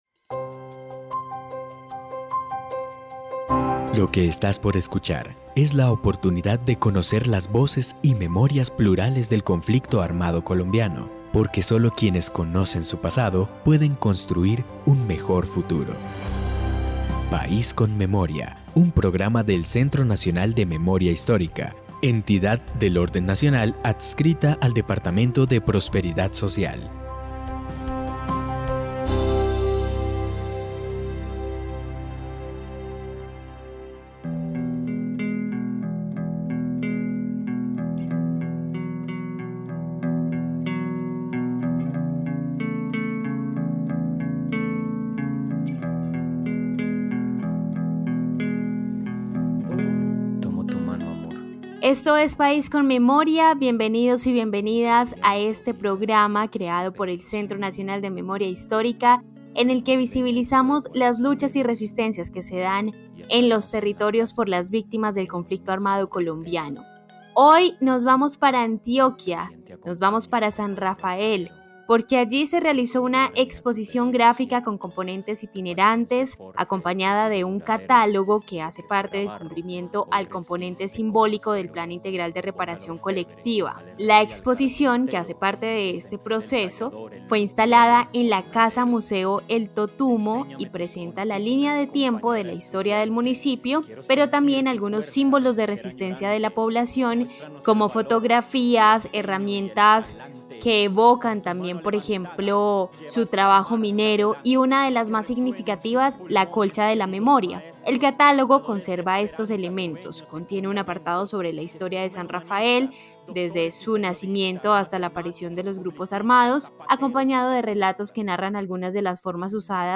Relatos testimoniales.
En País con Memoria hablamos con dos de las participantes de este proceso, quienes integran el Comité de Impulso para la Reparación Colectiva de San Rafael.